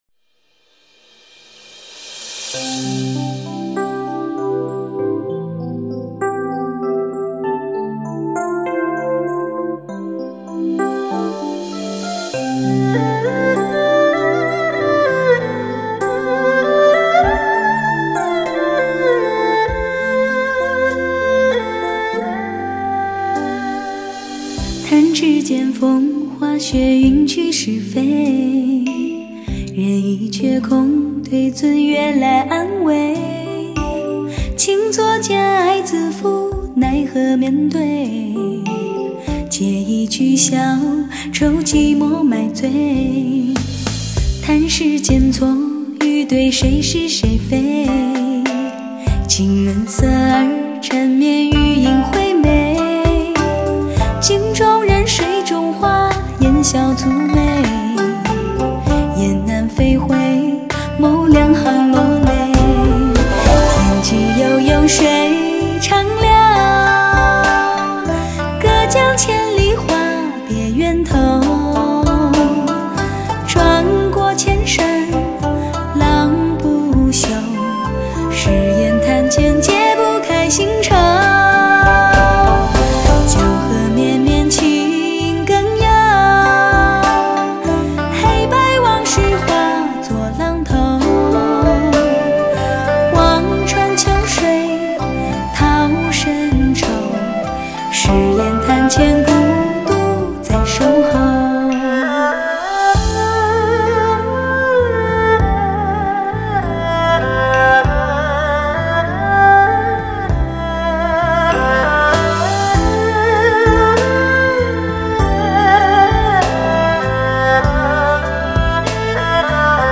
至尊汽车音响发烧试听珍藏，一种爱不释耳的绝色佳音，让你回味无穷！